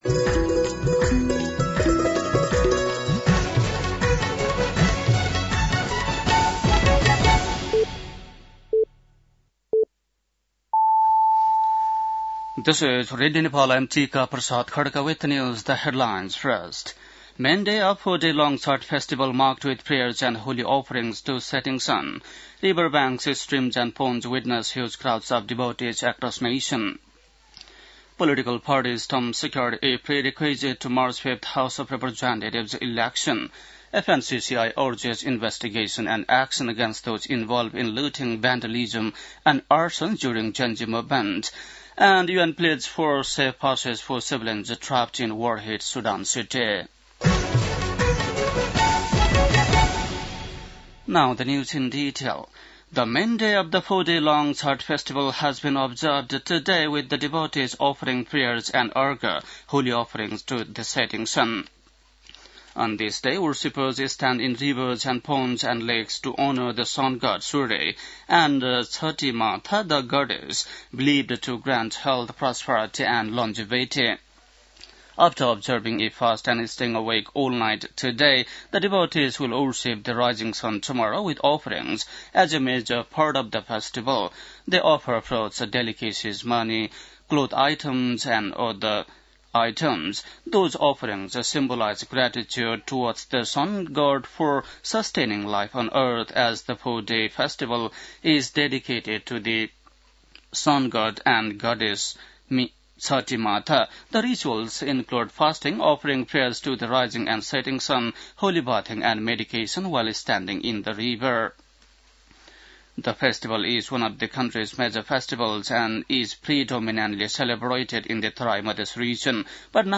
बेलुकी ८ बजेको अङ्ग्रेजी समाचार : १० कार्तिक , २०८२
8-PM-English-NEWS-7-10.mp3